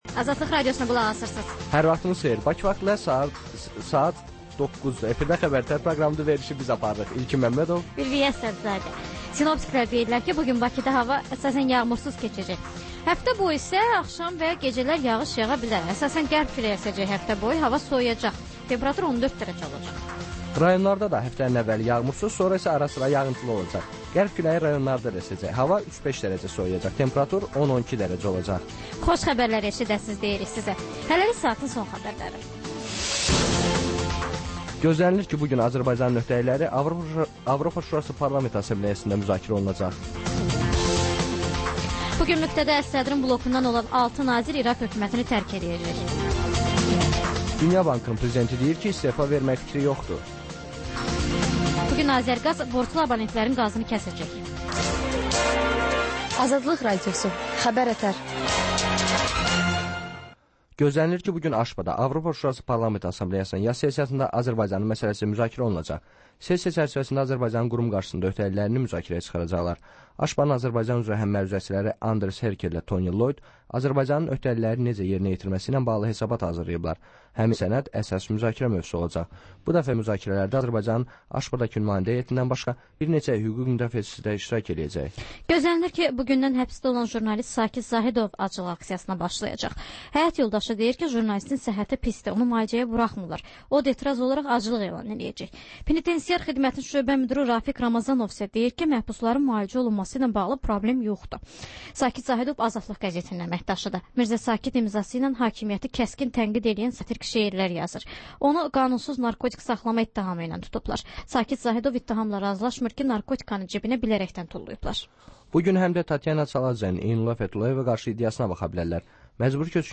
Xəbərlər, müsahibələr.